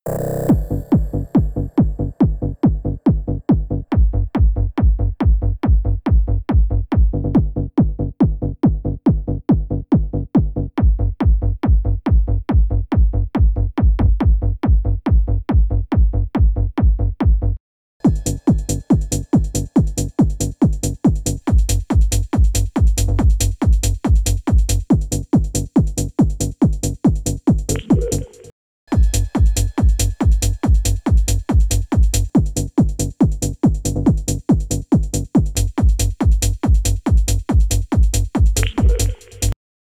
Kennt sich hier jemand mit Trance aus?
die Kick tiefer in den Subbass verlagert und den restlichen Bass etwas in die Mitten verschoben.
Durch die Anhebungen der Höhen in der Seite kommen die Percussion auch besser raus, wirken räumlicher.
Anschließend noch mit einem parametrischen EQ die Höhen in der Seite angehoben.